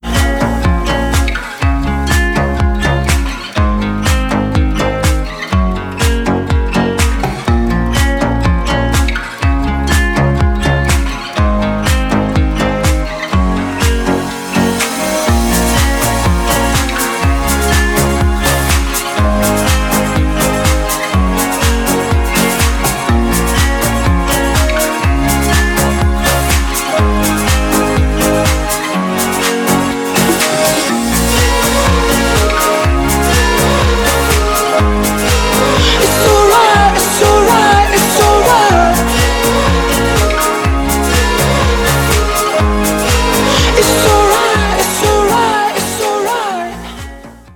• Качество: 320, Stereo
гитара
deep house